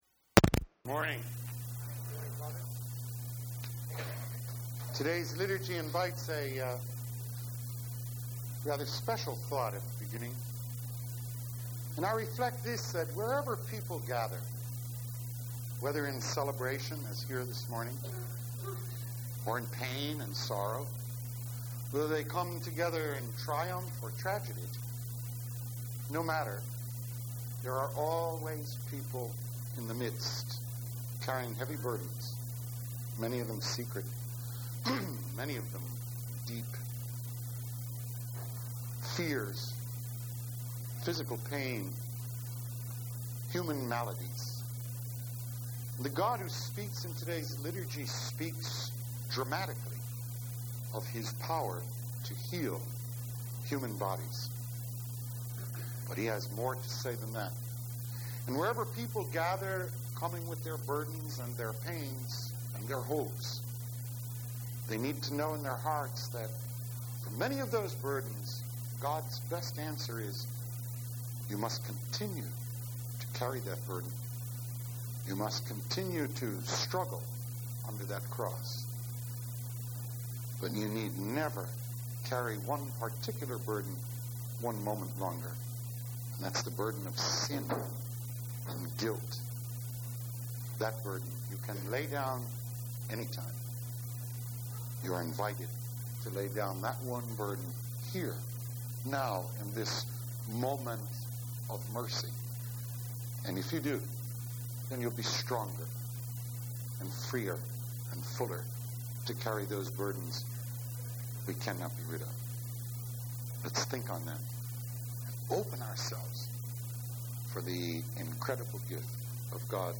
HIV/AIDS – Weekly Homilies